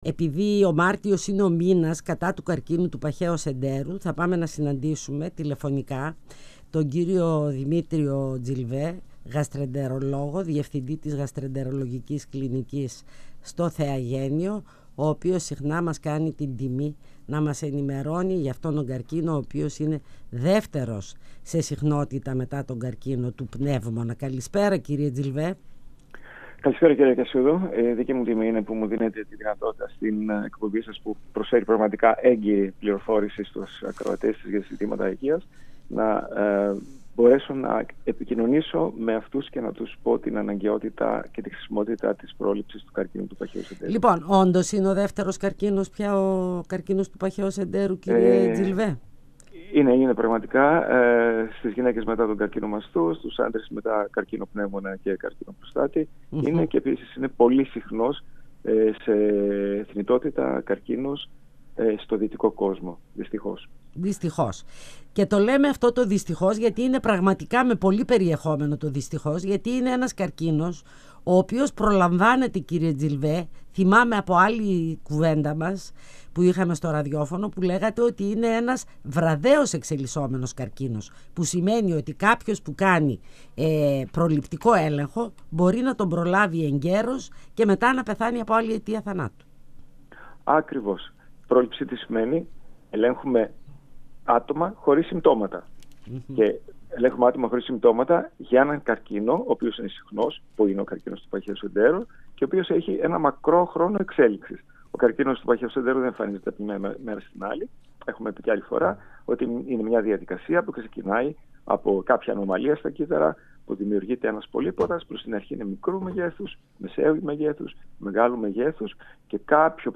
Φωνες Πισω απο τη Μασκα Συνεντεύξεις